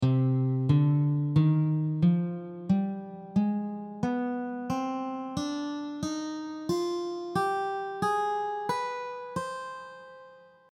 Here is the notation with tabs and audio for the C harmonic minor scale played in two octaves.
Harmonic-minor-scale-audio.mp3